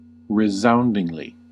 Ääntäminen
Ääntäminen US RP : IPA : /rɪˈzaʊndɪŋli/ US : IPA : /rɪˈzaʊndɪŋli/ Haettu sana löytyi näillä lähdekielillä: englanti Käännöksiä ei löytynyt valitulle kohdekielelle.